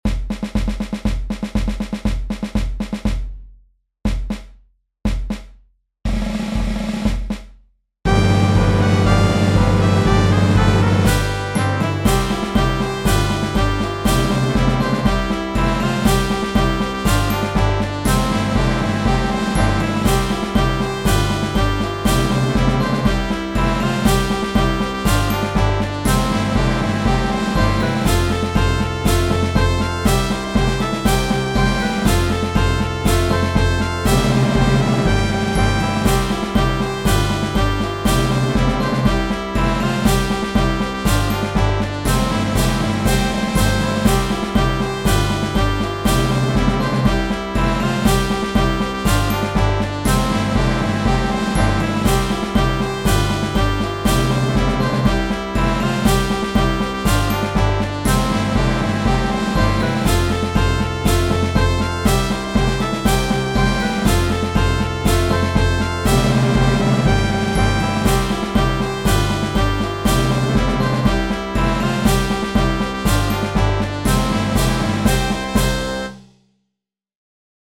EMU Proteus 2 synthesizer arrangement
Kept in my simplistic style that I prefer to do, this is for: 2 B-flat trumpets Horn in F (or French horn) Trombone Tuba Timpani Glockenspiel Snare drum Bass drum Cymbals Original music is now public domain, but originating from music by Jacques Offenbach, the same man who gave us the Can-Can Dance!
patriotic music